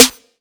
Snare Youza 1.wav